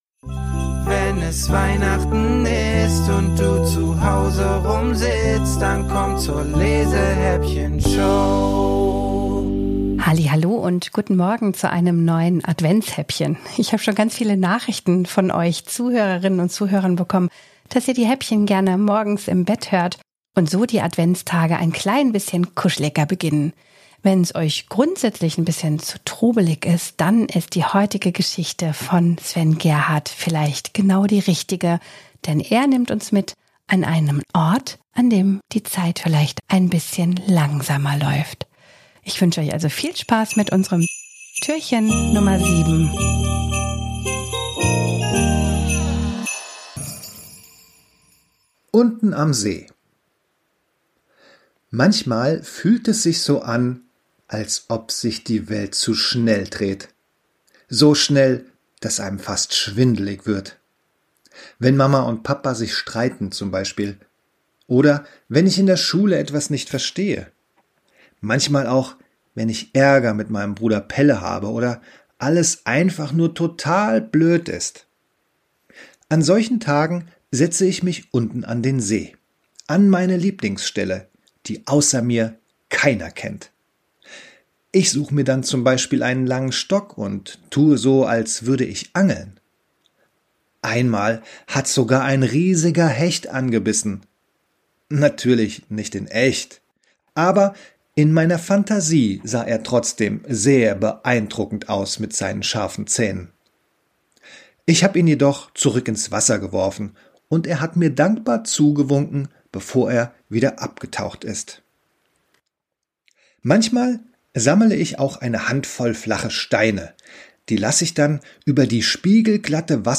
Gedichte, die extra für Euch und die LESEHÄPPCHEN-Show von den
Autorinnen und Autoren höchstpersönlich vertont wurden!